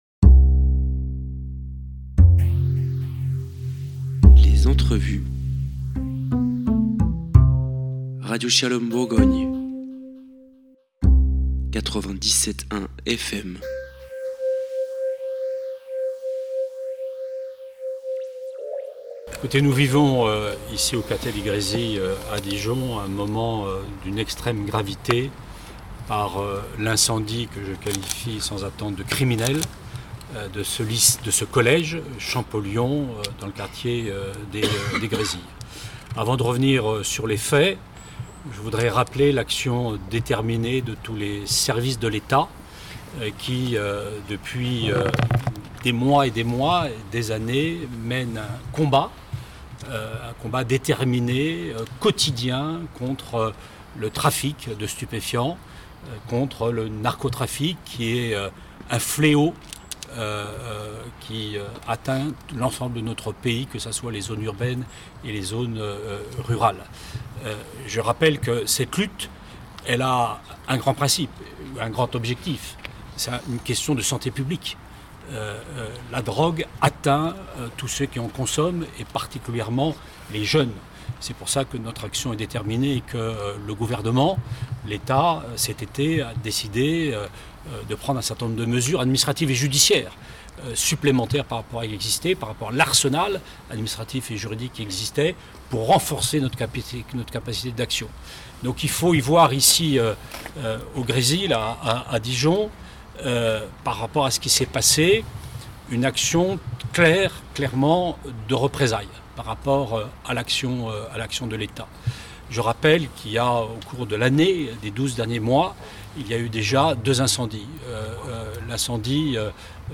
« Shalom Bourgogne » s’est rendue samedi 13 décembre au CollègeChampollion au point presse organisé par Monsieur le Préfet avecMadame la maire de Dijon, Monsieur le président du Conseildépartemental de Côte d’Or et Madame la rectrice de l’Académie deDijon.
Vous entendrez leurs réactions et leurs réponses aux questionsdes journalistes.